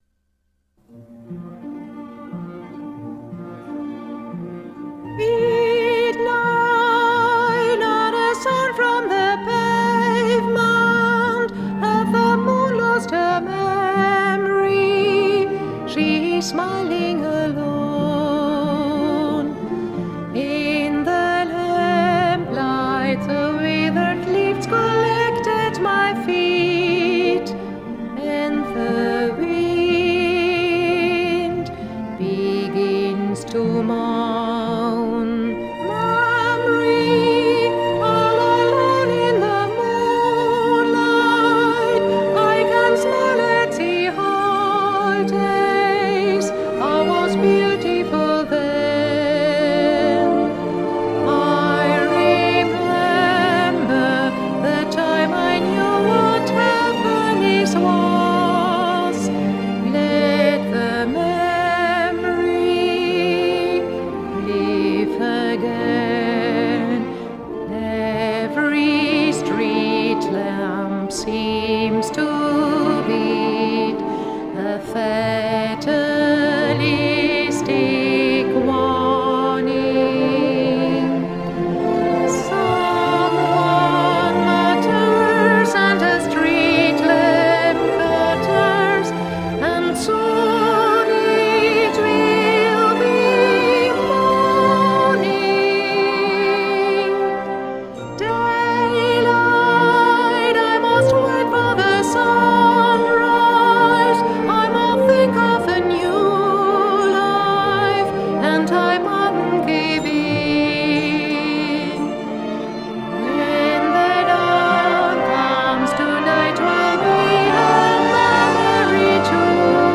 Sängerin